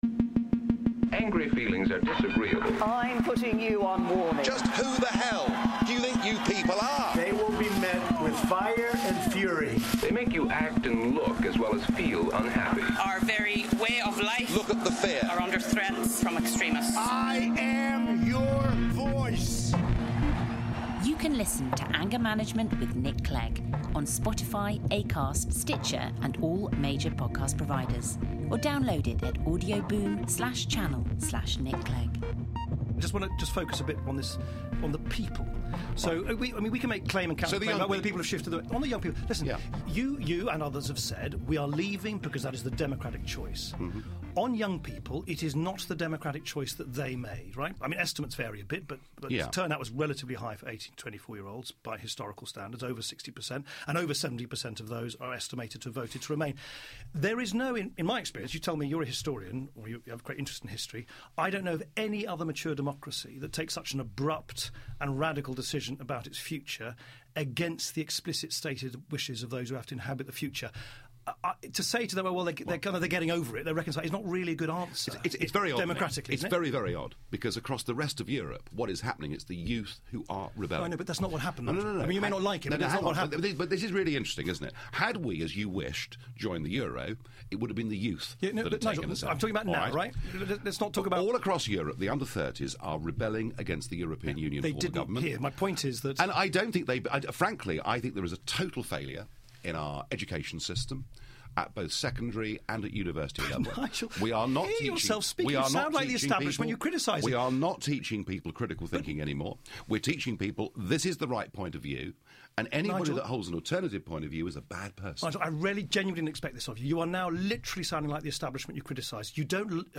In this clip, hear Nick lock horns with Farage over why imposing Brexit on a young generation that didn't vote for it is undemocratic.